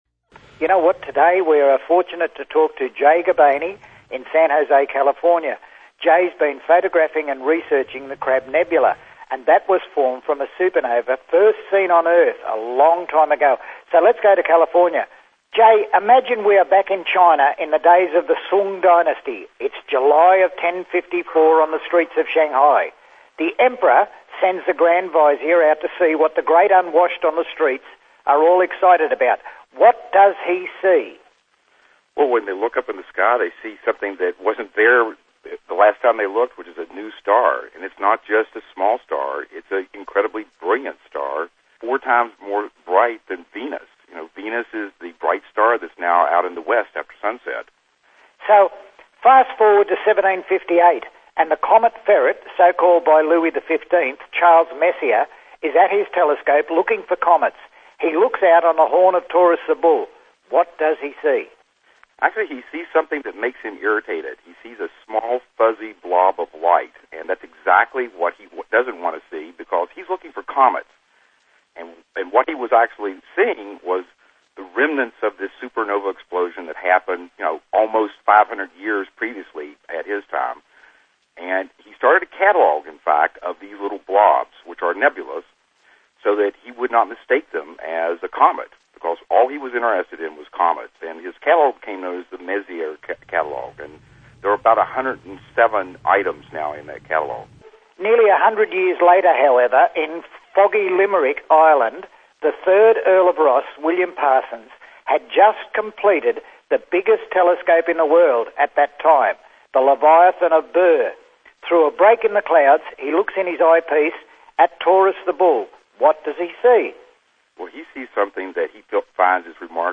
• Radio Inteviews